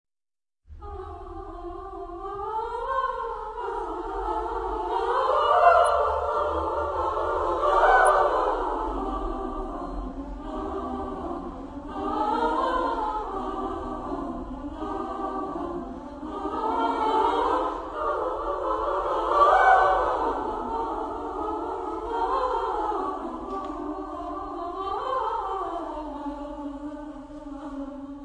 Texte en : vocalises
Genre-Style-Forme : Pièce vocale ; Profane
Type de choeur : SSA  (3 voix égales de femmes )
Solistes : Sopran (1)  (1 soliste(s))
Tonalité : libre
Réf. discographique : 4.Deutscher Chorwettbewerb, 1994